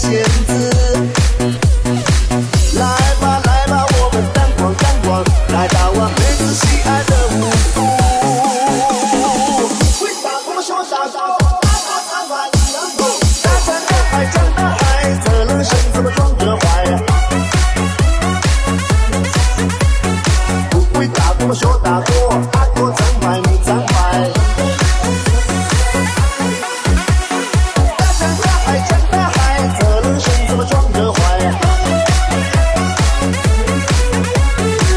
Mandopop Pop
Жанр: Поп музыка